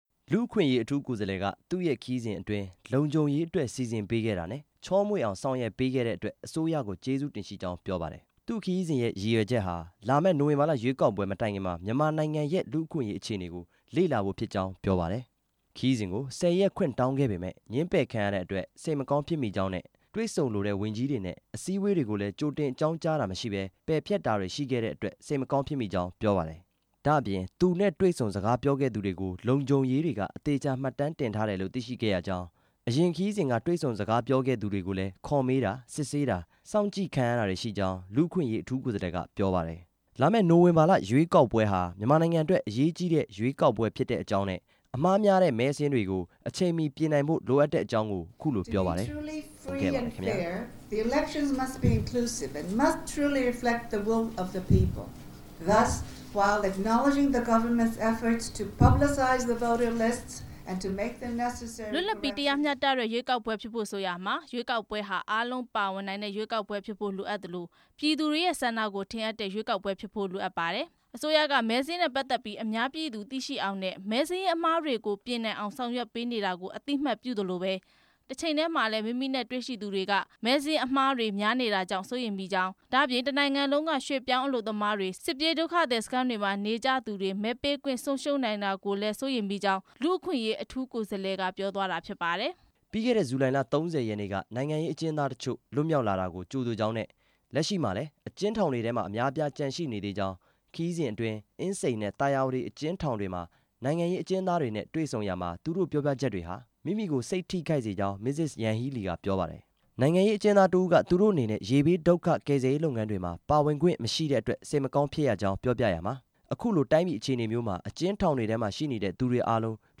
မစ္စ ရန်ဟီးလီရဲ့ သတင်းစာရှင်းလင်းပွဲအကြောင်း တင်ပြချက်